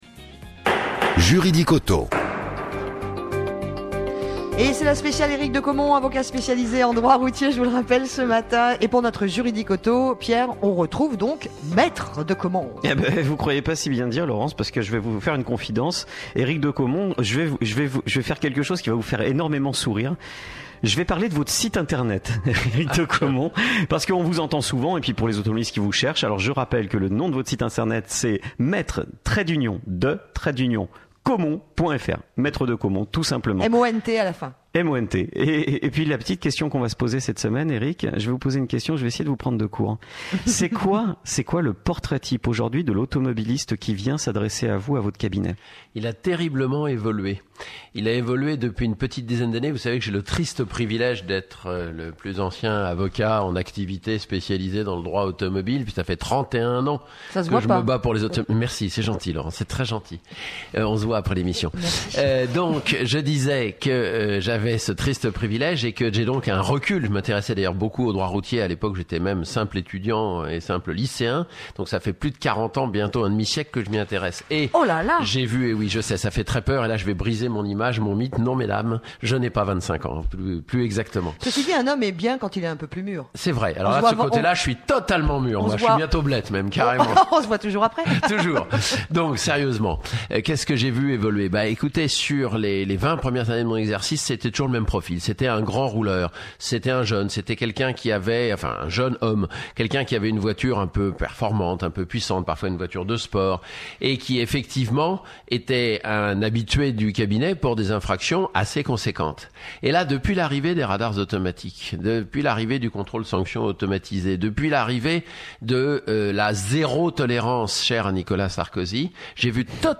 Débat Pleins Phares : Les nouveaux radars embarqués